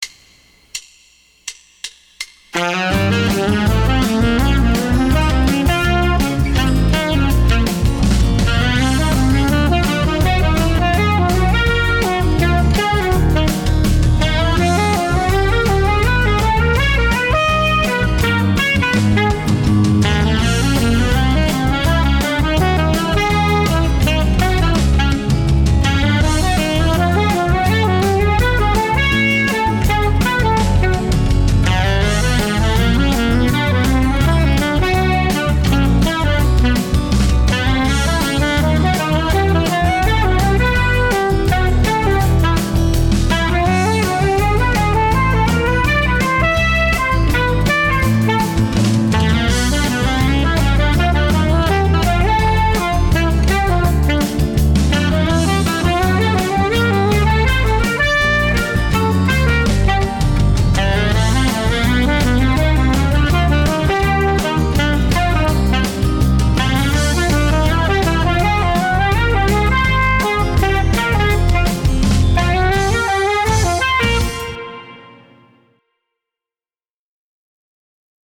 (For charts in other keys or clefs, send me a comment listing your instrument, key, range, and preferred clef.)This song originated as a four-bar exercise which I was wood-shedding in all 12 keys. Below is a recording of that effort along with a chart, if you would like to work on the lick.
slippery-slope-exercise-12-keys.mp3